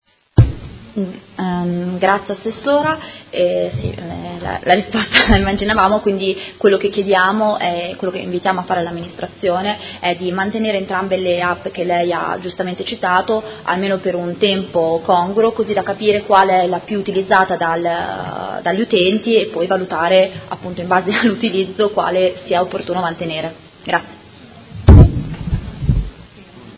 Elisabetta Scardozzi — Sito Audio Consiglio Comunale